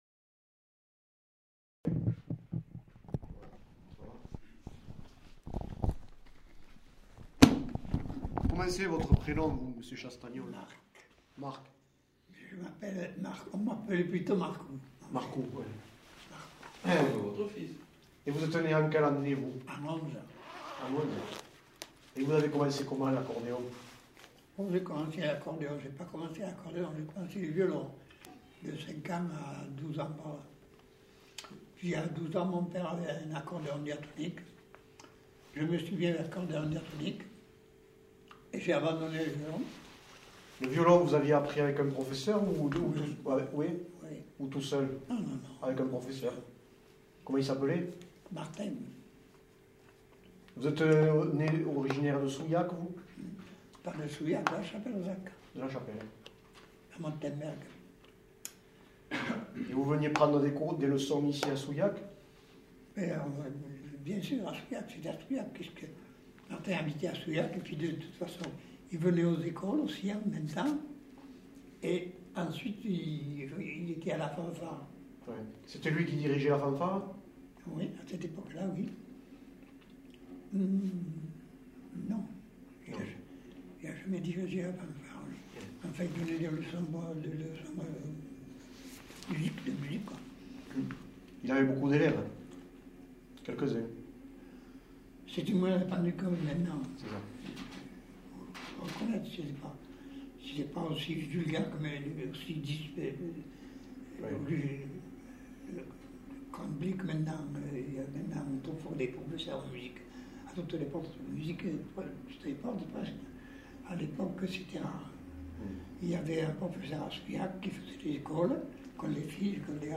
Genre : récit de vie Descripteurs : pratique musicale ; apprentissage musical ; musicien ; bal